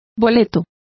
Complete with pronunciation of the translation of coupons.